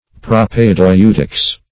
propaedeutics - definition of propaedeutics - synonyms, pronunciation, spelling from Free Dictionary
Propaedeutics \Pro`p[ae]*deu"tics\, n.